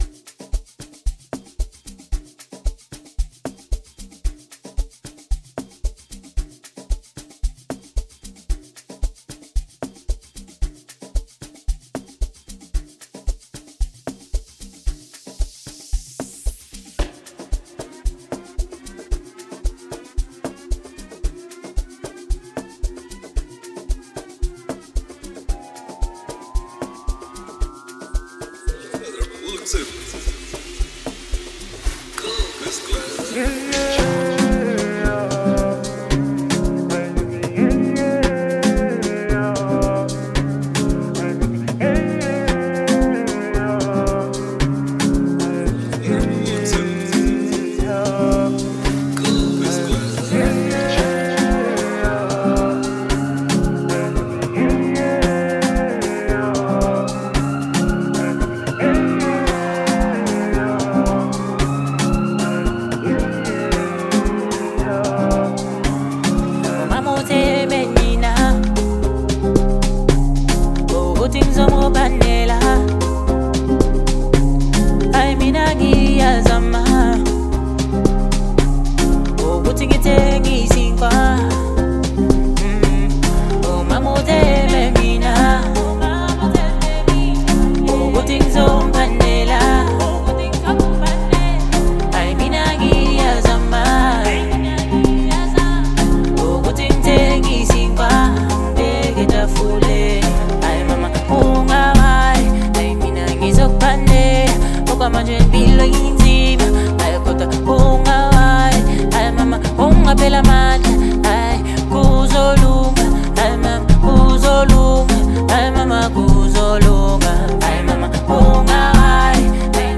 who spit scathing verses back and forth.